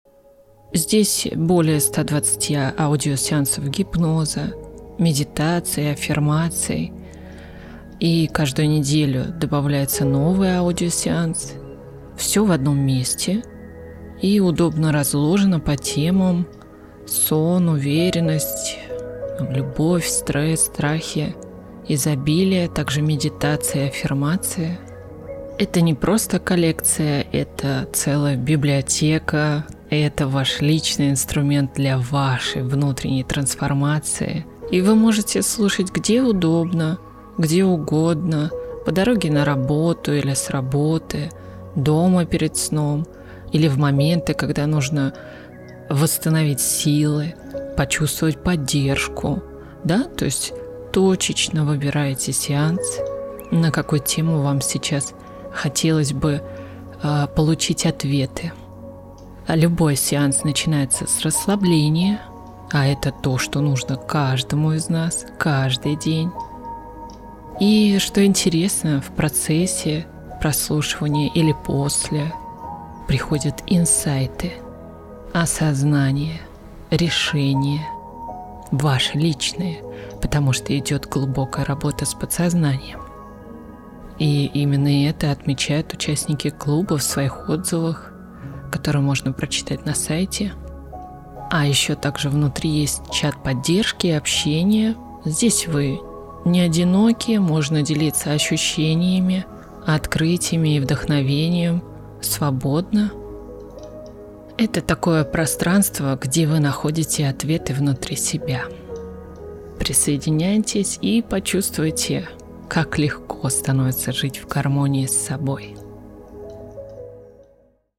Библиотека аудиосеансов гипноза, медитаций и аффирмаций
с_музыкой_подписка.mp3